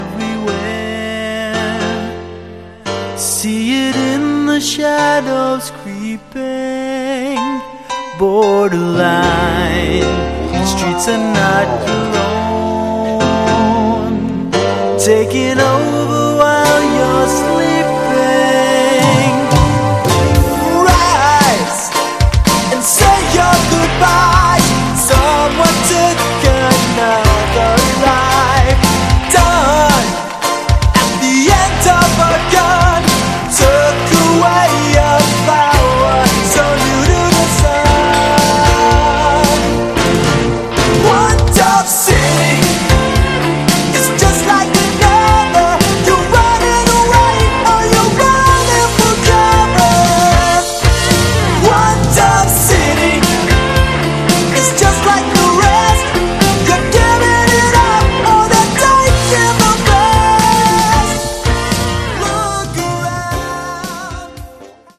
Category: AOR/Hard Rock
drums, keyboards
guitars, basses